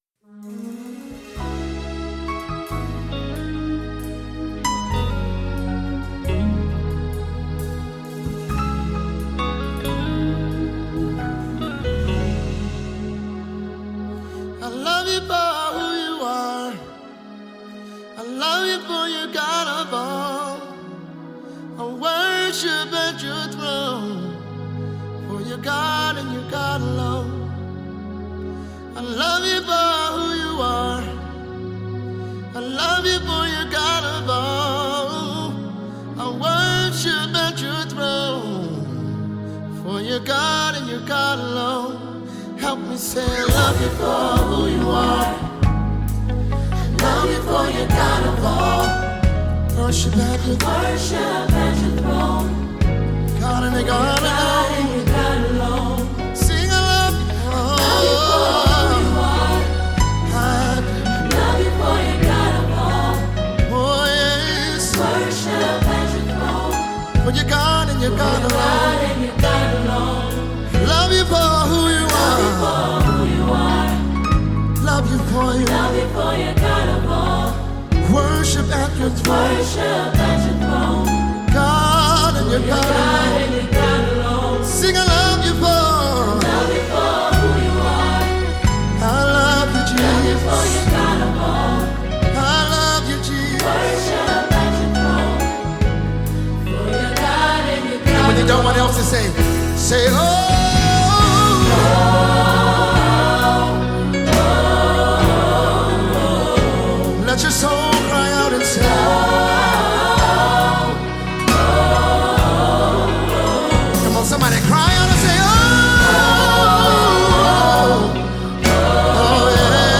Mp3 Gospel Songs
an American gospel singer, songwriter, and music pastor.